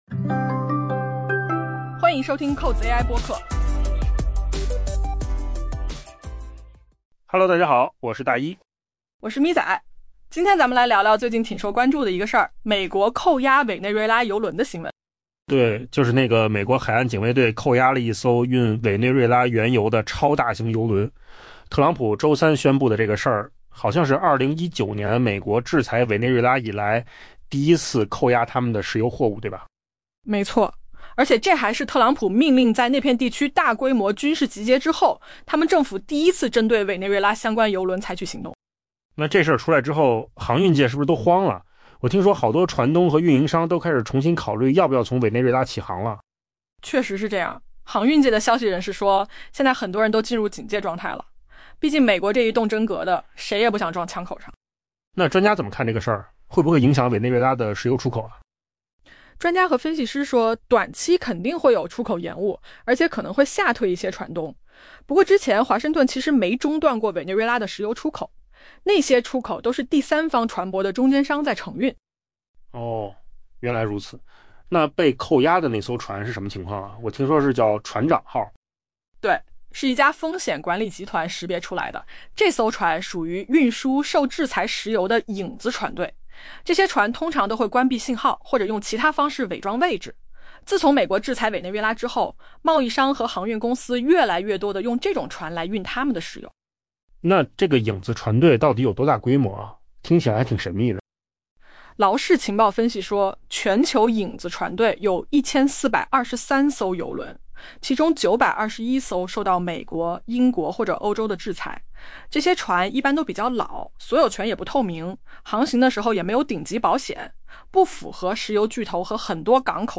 AI 播客：换个方式听新闻 下载 mp3 音频由扣子空间生成 路透社报道称，在 美国海岸警卫队扣押了一艘运载委内瑞拉出口原油的超大型油轮 后， 航运数据显示，在委内瑞拉开展业务的超过 30 艘受美国制裁的油轮可能面临华盛顿的惩罚。